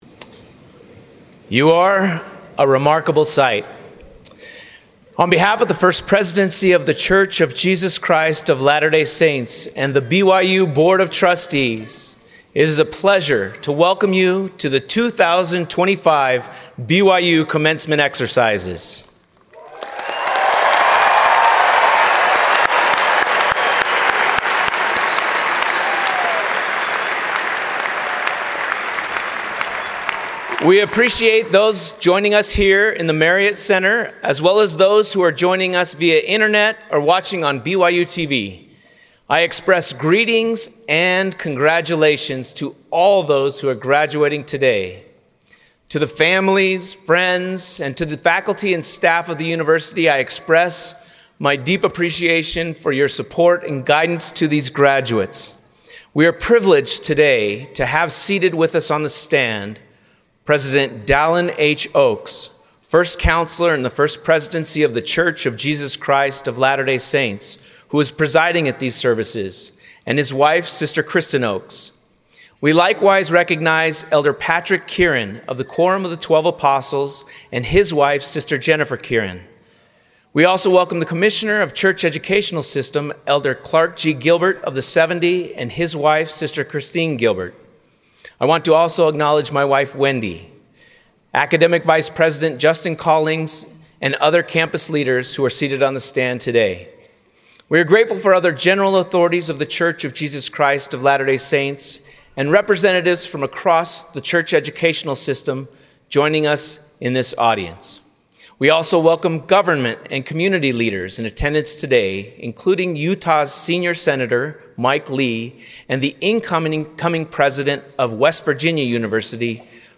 Audio recording of Grab a Shovel: Commencement 2025 by C. Shane Reese